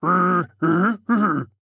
AV_bear_long.ogg